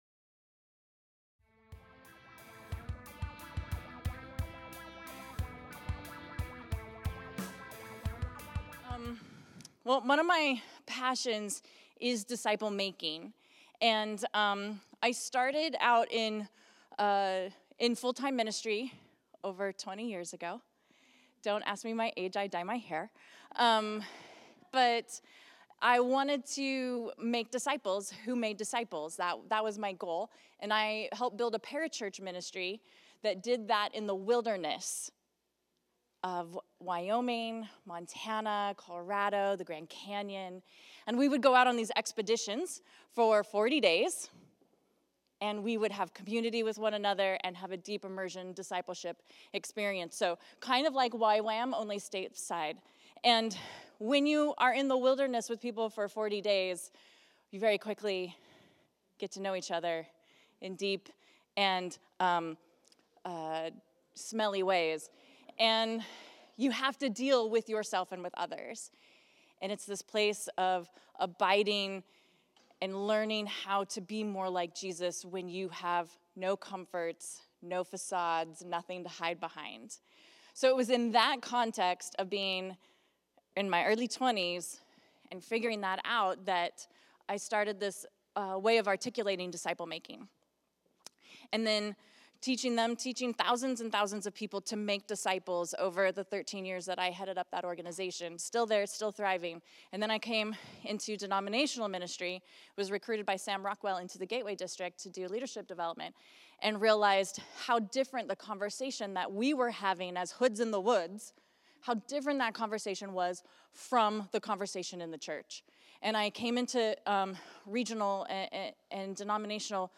Workshop recording from the 2022 Foursquare Leadership and Education Forum.